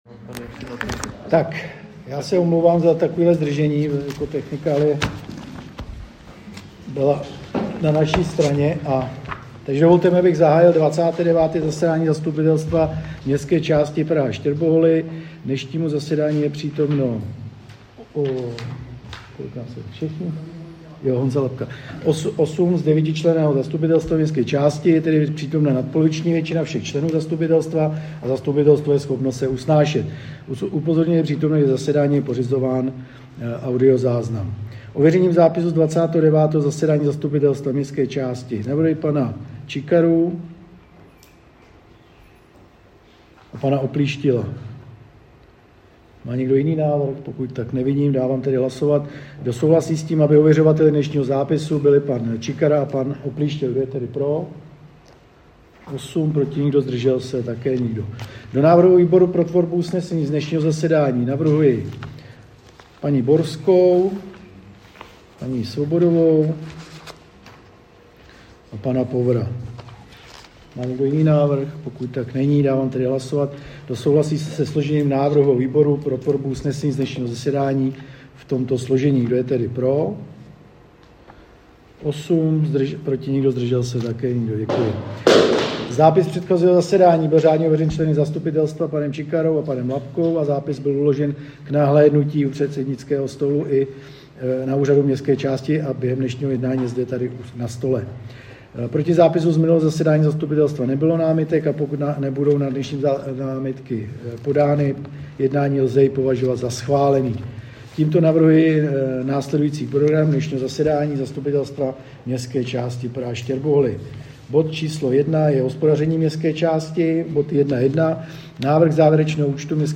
Zvukový záznam z 29.zasedání Zastupitelstva MČ Praha Štěrboholy